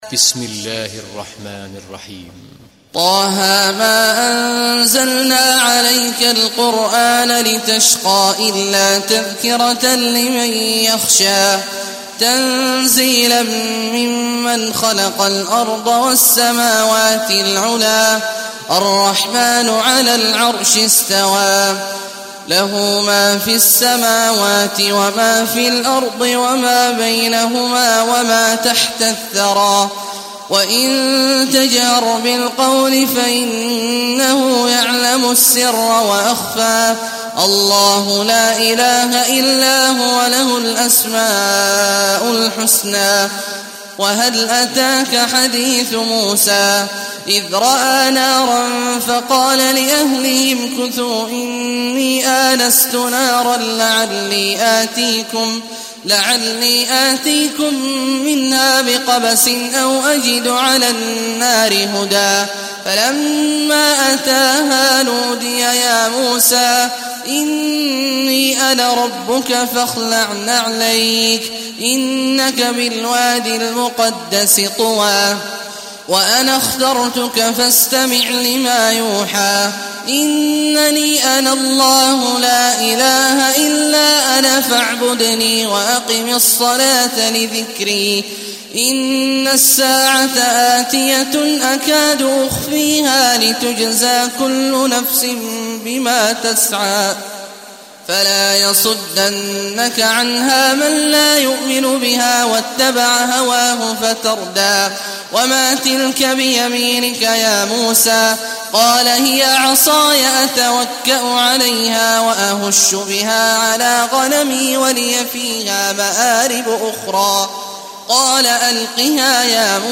Sourate Taha Télécharger mp3 Abdullah Awad Al Juhani Riwayat Hafs an Assim, Téléchargez le Coran et écoutez les liens directs complets mp3